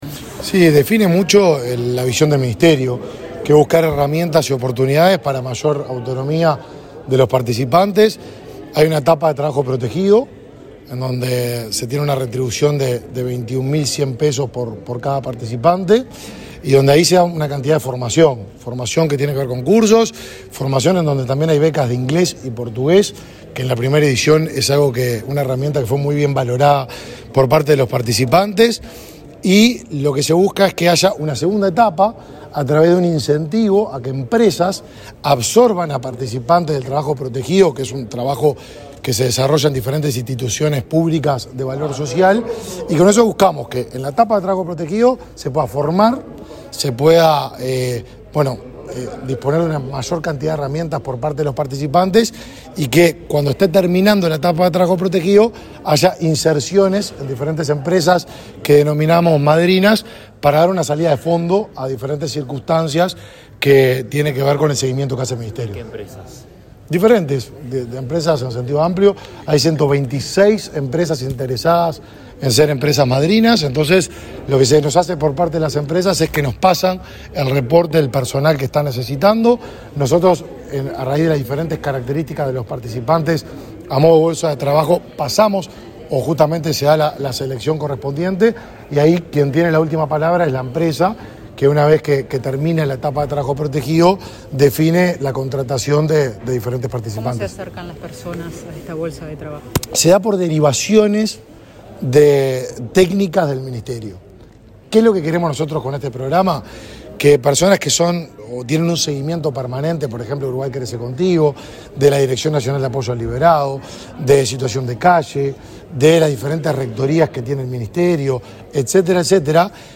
Declaraciones del ministro de Desarrollo Social, Martín Lema
Declaraciones del ministro de Desarrollo Social, Martín Lema 02/05/2023 Compartir Facebook X Copiar enlace WhatsApp LinkedIn Este martes 2 de mayo, el Ministerio de Desarrollo Social (Mides) presentó la segunda edición del programa socioeducativo y laboral Accesos. El titular de la cartera, Martín Lema, participó en el acto y luego dialogó con la prensa.